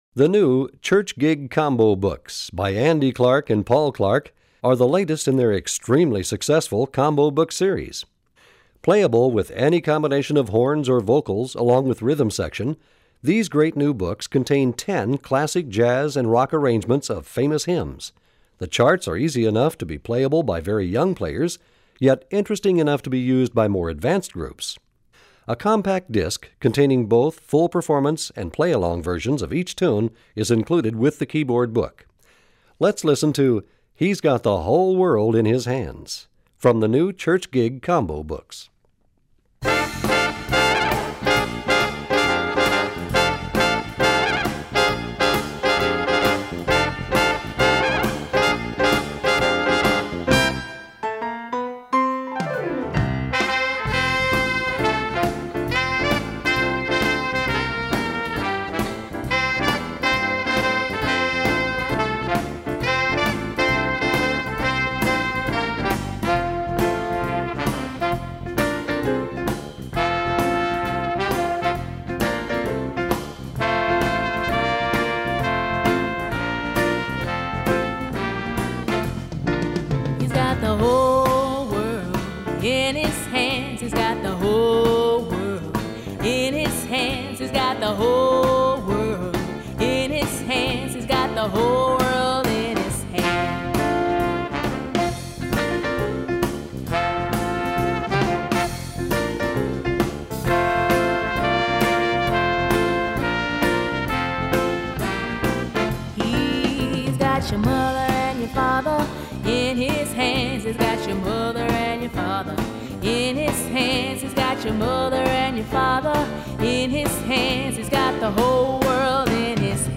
Voicing: Bb Instruments